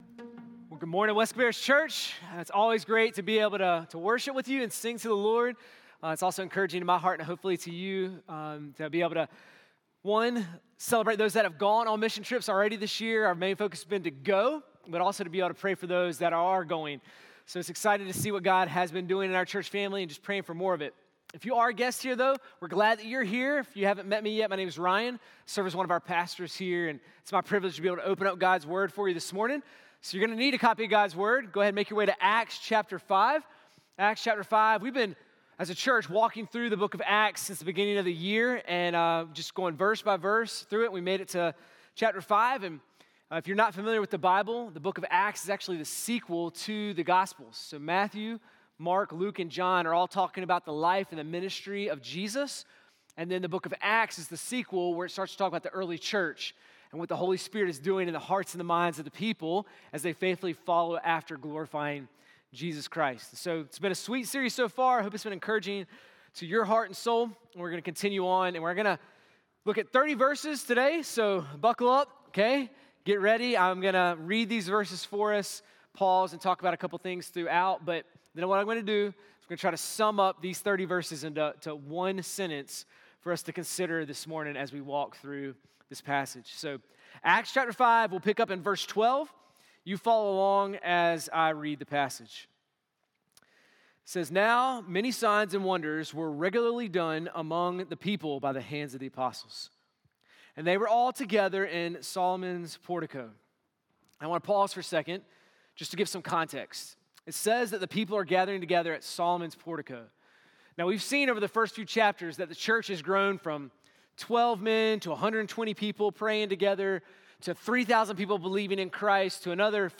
sermon-3-1-26.mp3